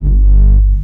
reversed kick.wav